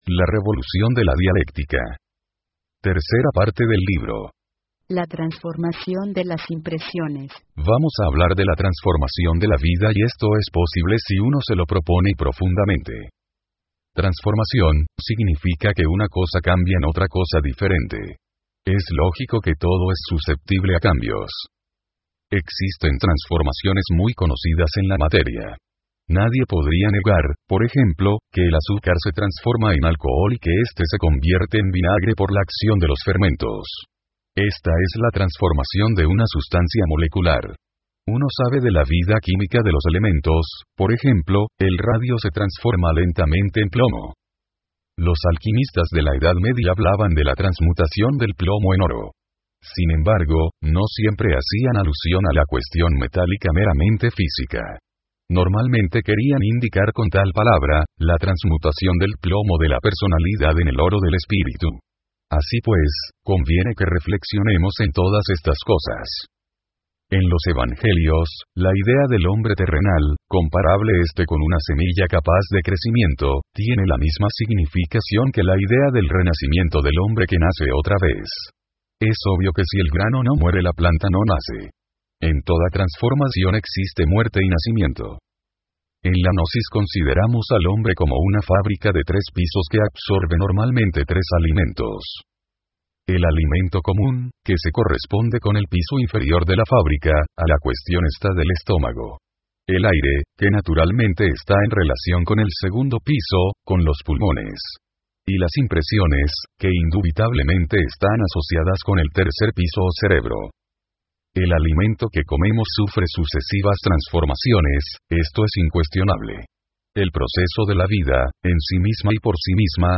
Audiolibros del maestro Samael Aun Weor